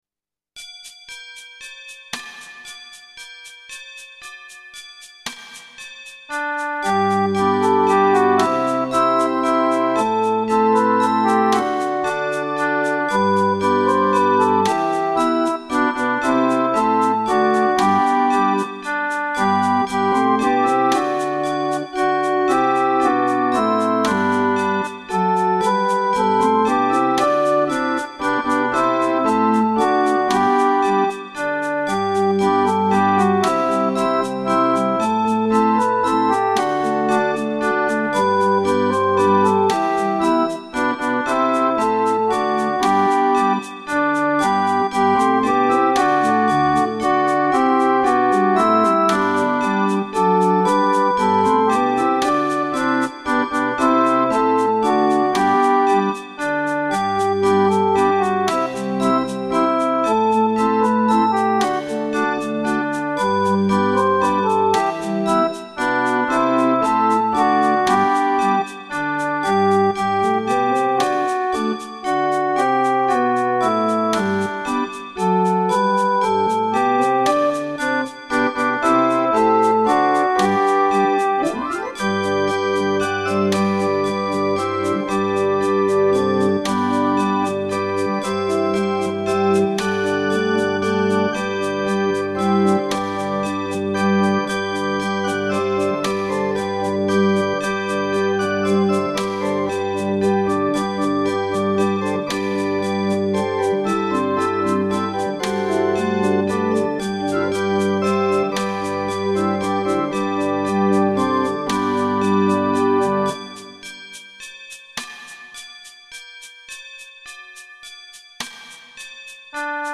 イギリス民謡
使用機材：Singer Song Writer 8.0 VS, KORG X3, KORG D1200mkII
（公開されている音源にはボーカルは収録されていません）